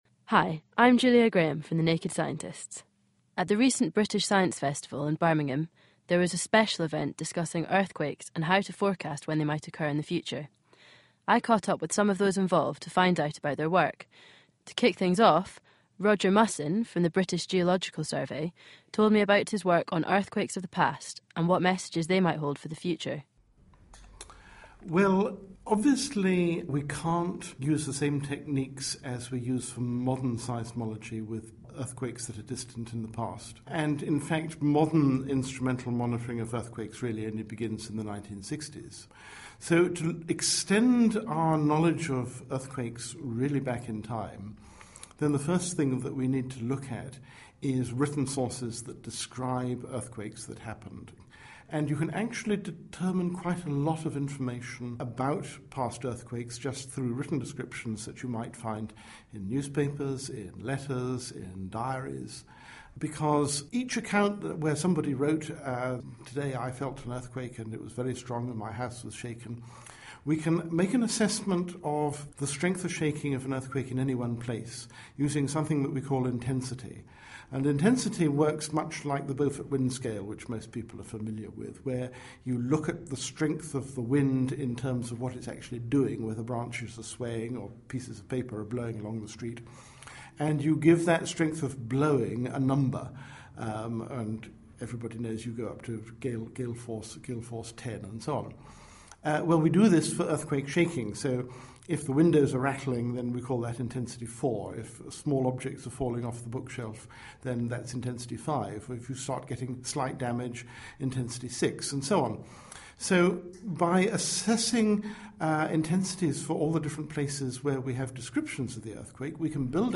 What do we know about earthquakes? And can we predict when they might occur? A special event was held to discuss these...